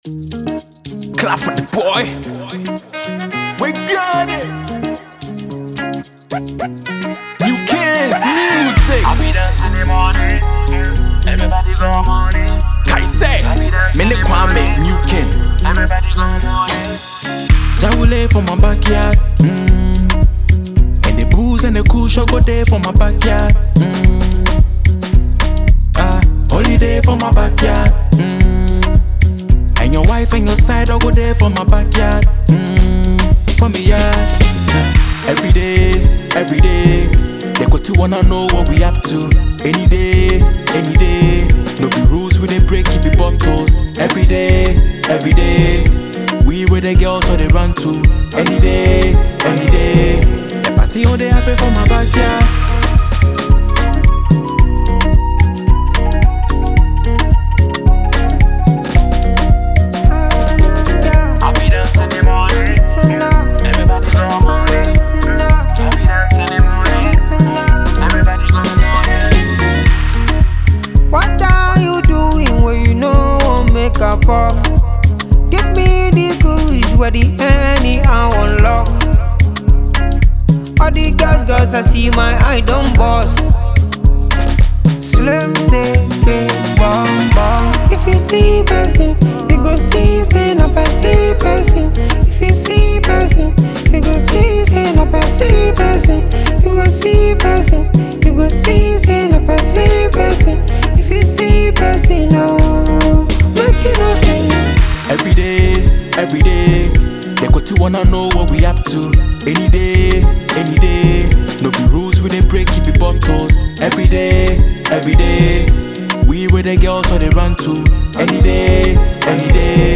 offering fans a feel good anthem that celebrates success